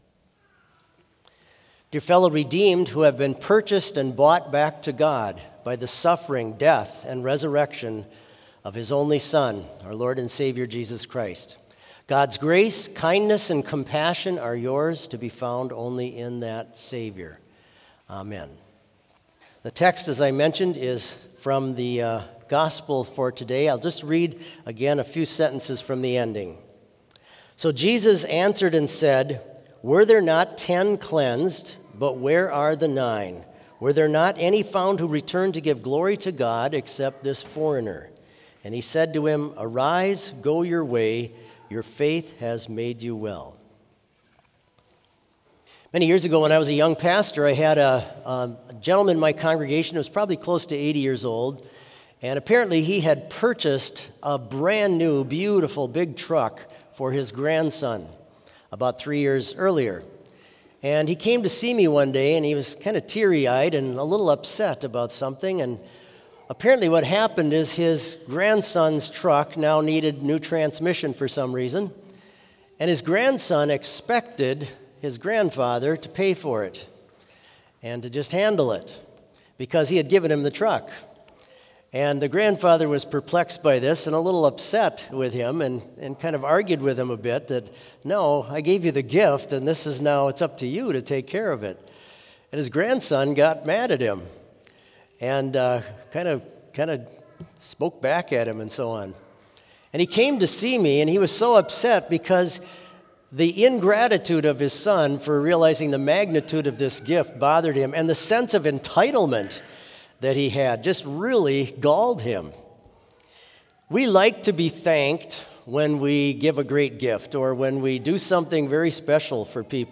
Complete service audio for Special - Sunday, September 21, 2025
v. 1 Soloist, v. 2 Choir, v. 3, 4 All
1 All, 2 Women, 3 Men, 4 All, 5 Women, 6 Men, 7 All